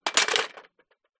Звуки самоката
Звук удара металлического самоката о землю